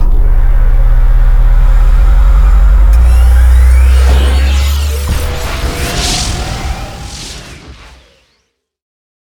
partlaunch.ogg